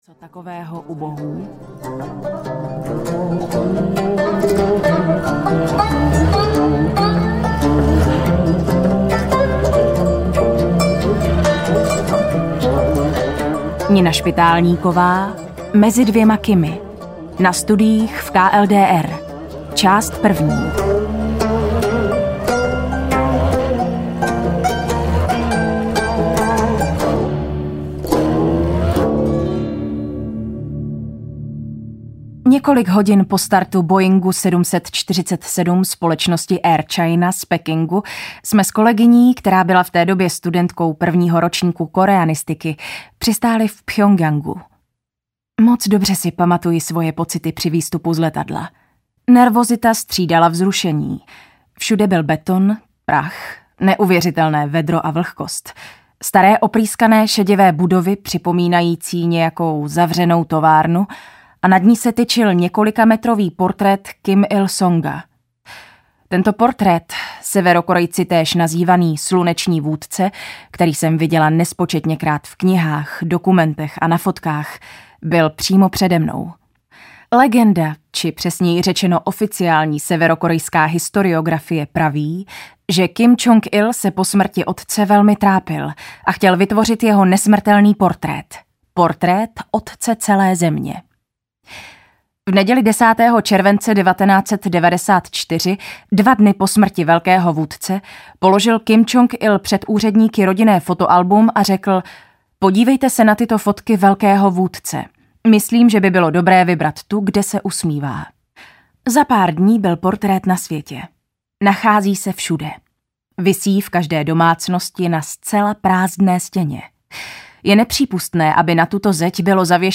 Mezi dvěma Kimy audiokniha
Ukázka z knihy